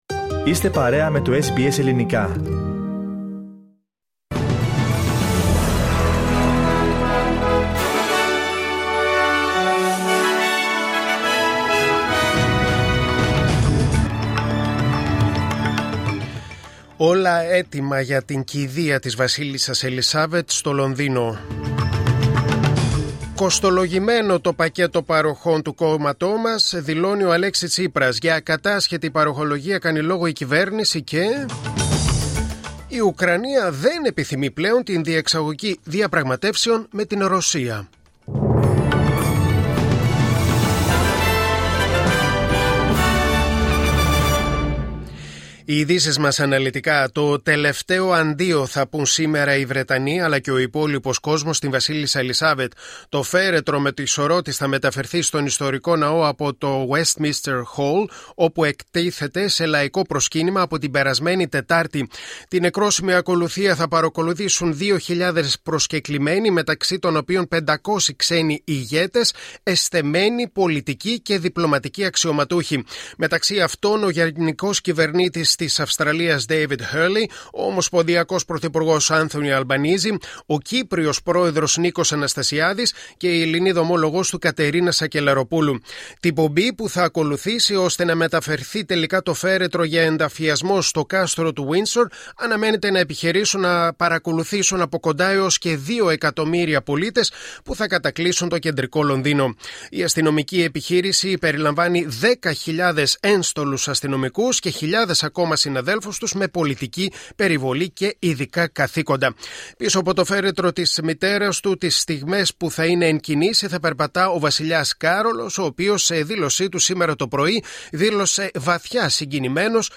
Δελτίο Ειδήσεων: Δευτέρα 19.9.2022